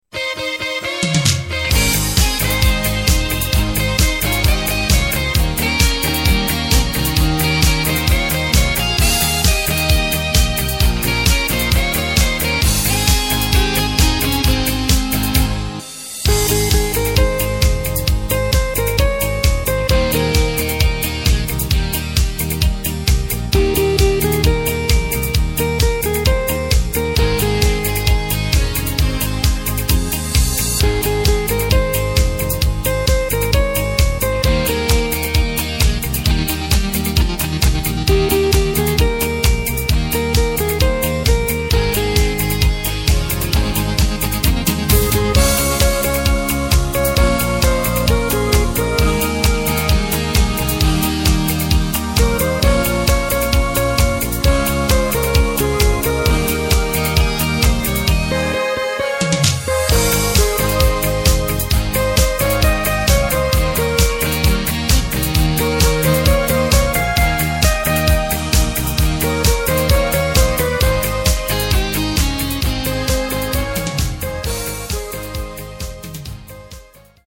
Takt:          4/4
Tempo:         132.00
Tonart:            Ab
Discofox aus dem Jahr 2006!
Playback mp3 Demo